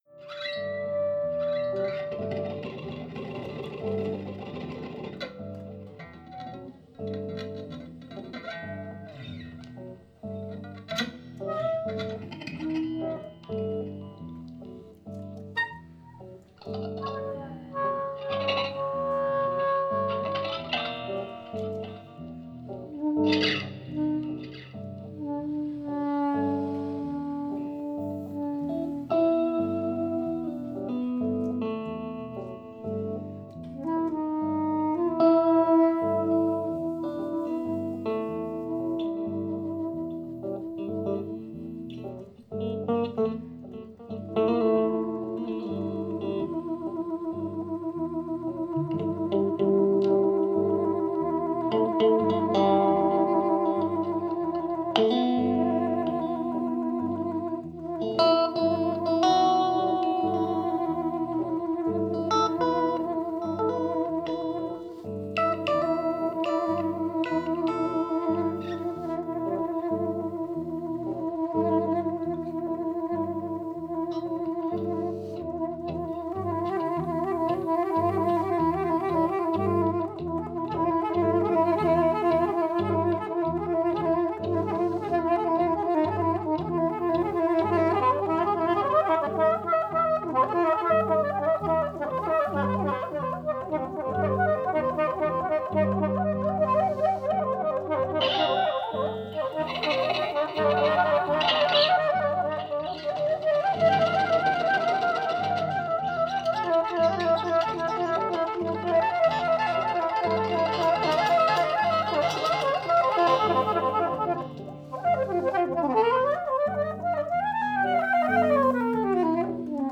From a session in march
soprano saxophone
electric guitar, looping
Sehr meditativ.